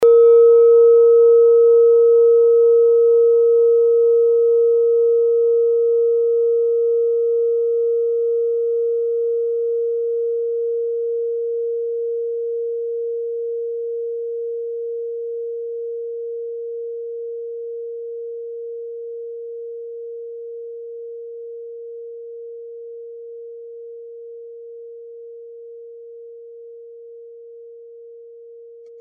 Klangschale Nepal Nr.2
Klangschale-Gewicht: 860g
ist der natürliche Kammerton bei 432Hz und dessen Ober- und Untertöne.
klangschale-nepal-2.mp3